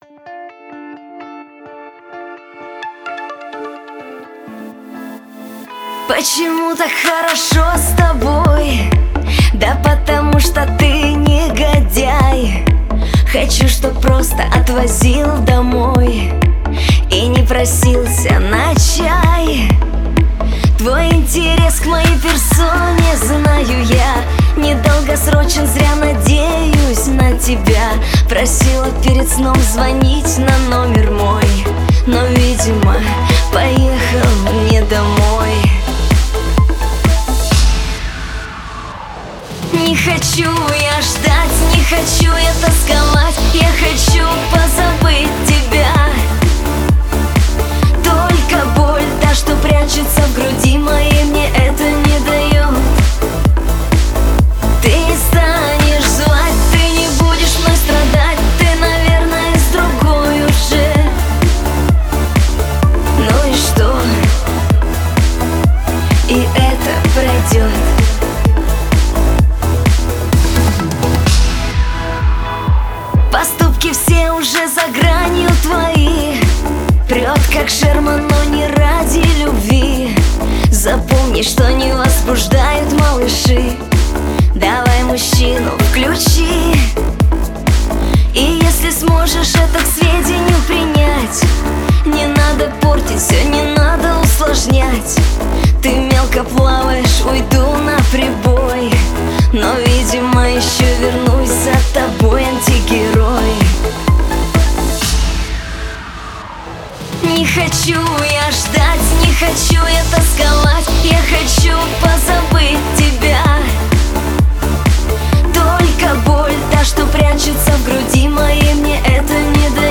И манера исполнения близки
К эстрадному направлению